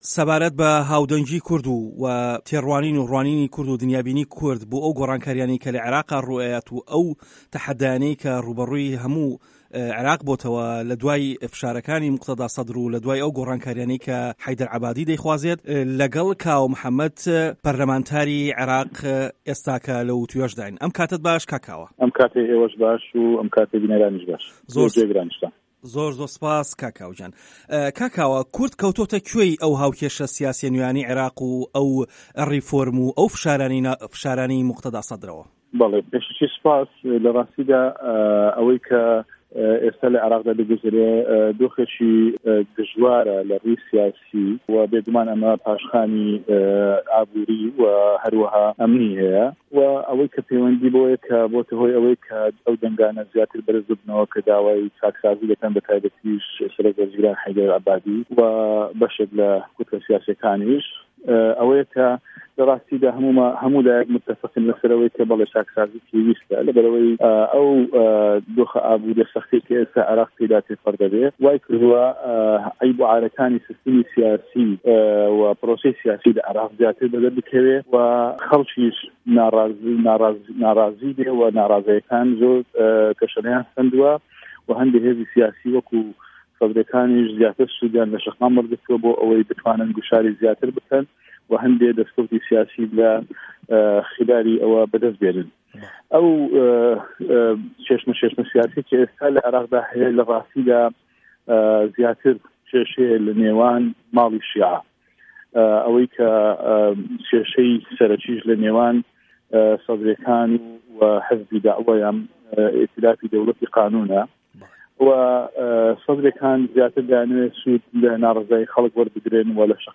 وتووێژ لەگەڵ کاوە محەمەد